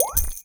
potion_flask_mana_collect_03.wav